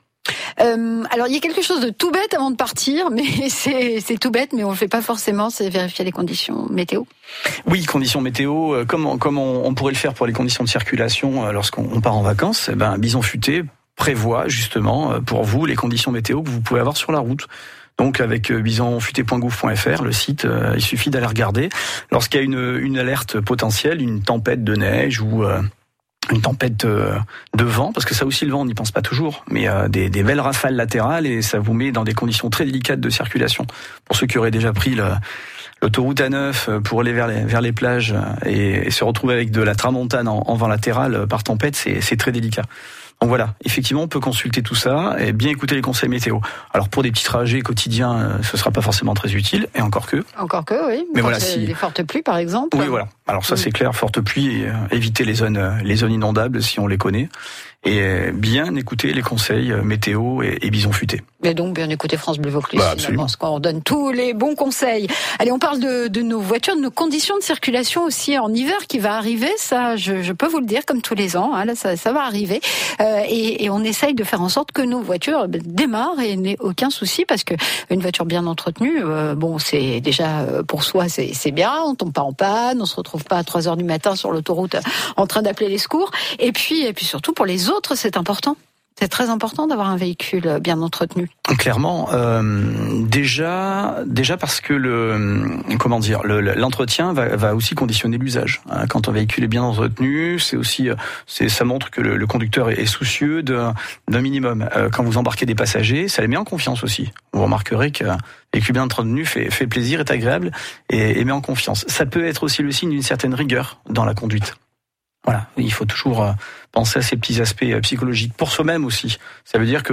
Interview © France Bleu Vaucluse